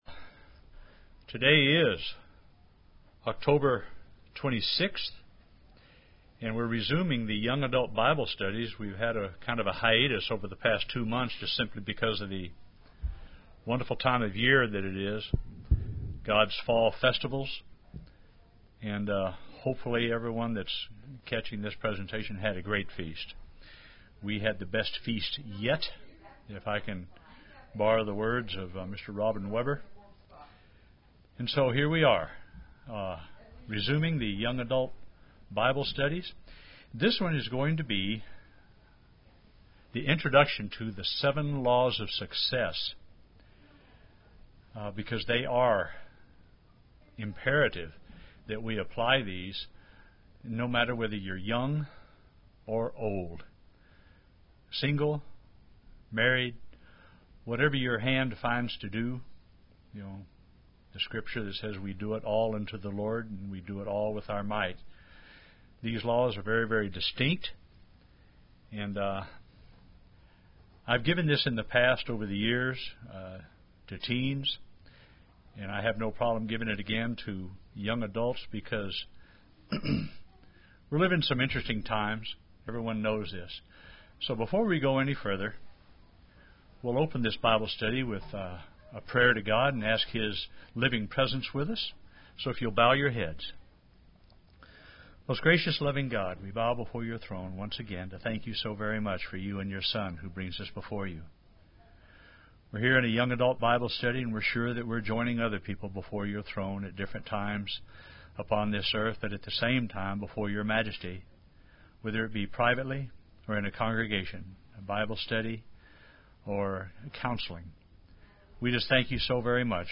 The first law of success is discussed in this Young Adult Bible Study.
Given in Oklahoma City, OK
UCG Sermon Studying the bible?